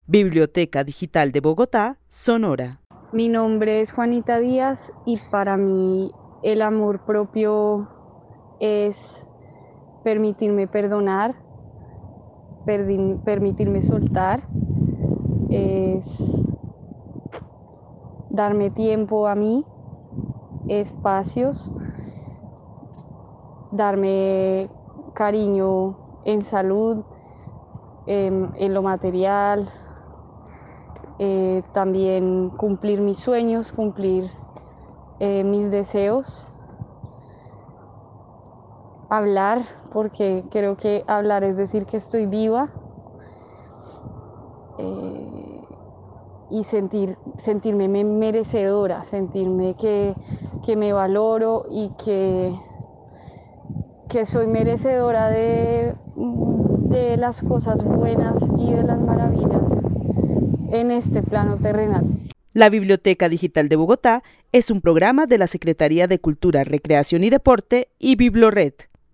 Narración oral de una mujer que vive en la ciudad de Bogotá que describe el amor propio como un proceso de perdonar y soltar, que incluye darse tiempo y espacios de cariño y cuidado. Para ella el amor propio es cumplir sus sueños, creerse merecedora y valorarse. El testimonio fue recolectado en el marco del laboratorio de co-creación "Postales sonoras: mujeres escuchando mujeres" de la línea Cultura Digital e Innovación de la Red Distrital de Bibliotecas Públicas de Bogotá - BibloRed.
Narrativas sonoras de mujeres